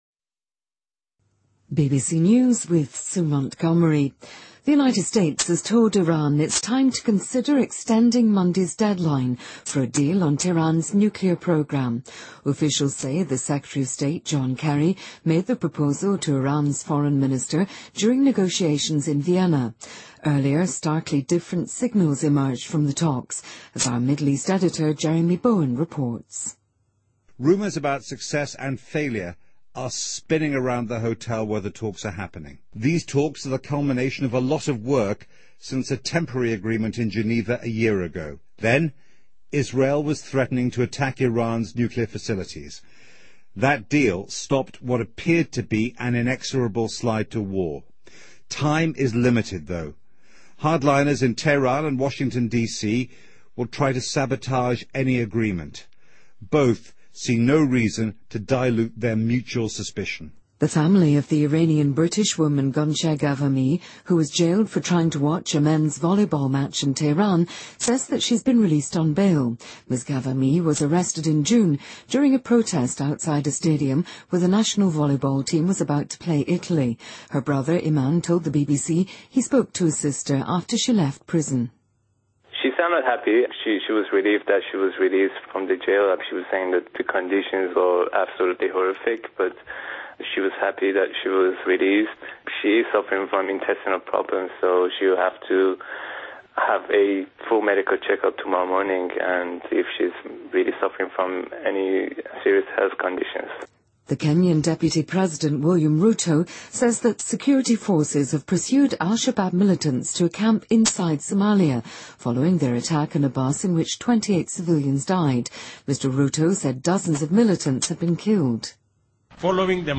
Date:2014-11-24Source:BBC Editor:BBC News